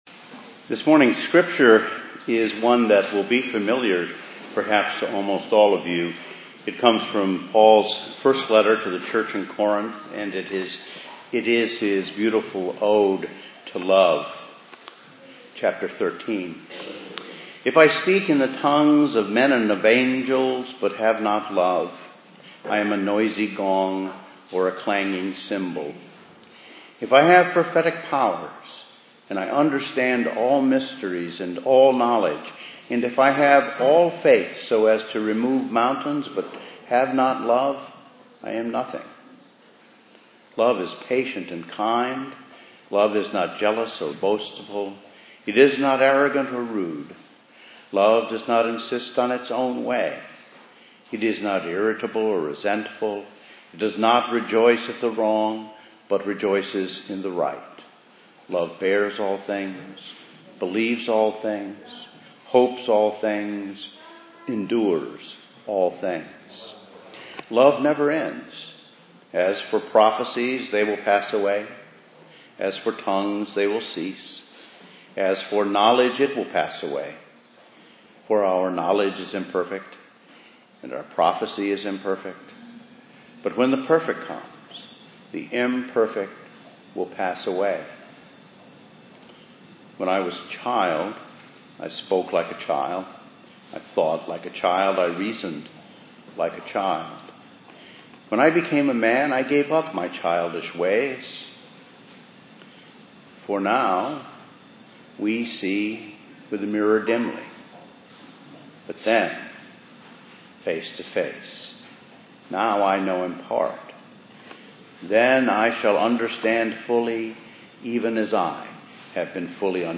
Passage: 1 Corinthians 13:1-13 Service Type: Sunday Morning